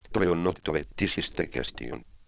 Además se pondrán ejemplos de una frase sintetizada con diferentes registros de voz, en las diferentes aplicaciones de síntesis.
•Audio generado con el Cotovía.
Fichero1 - Registro de hombre